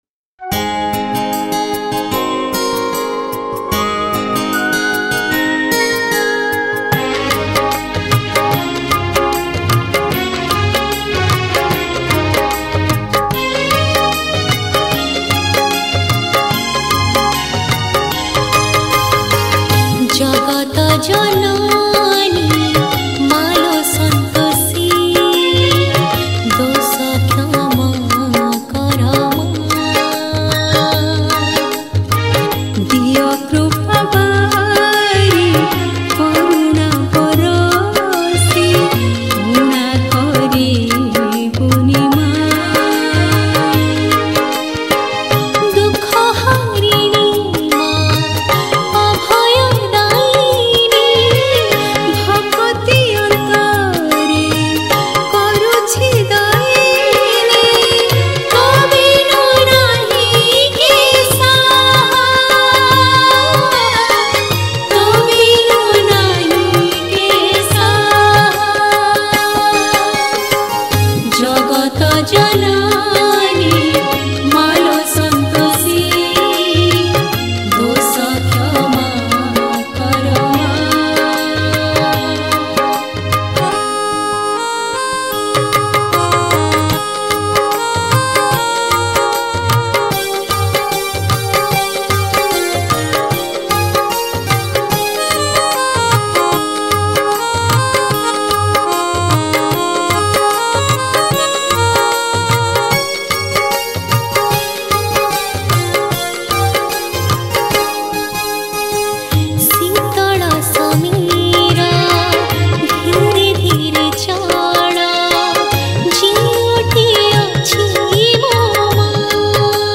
Category : Santosi Maa Jayanti Spcial Bhajan